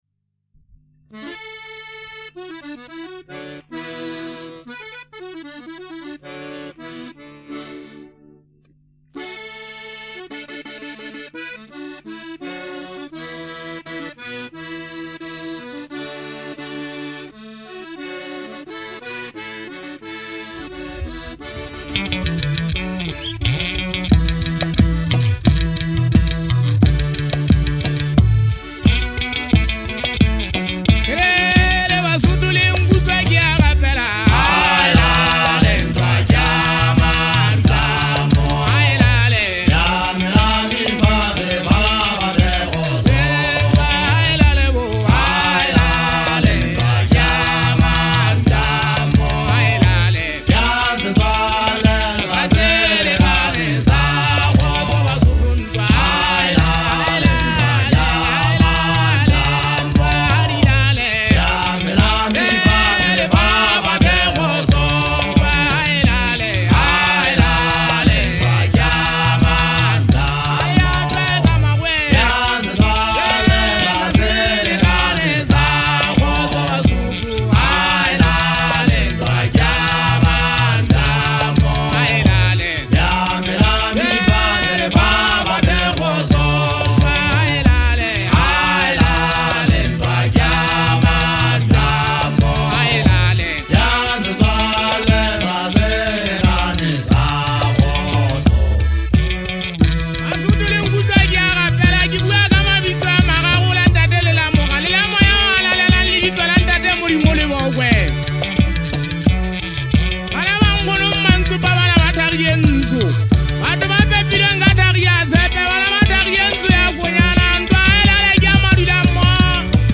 Amapiano, Lekompo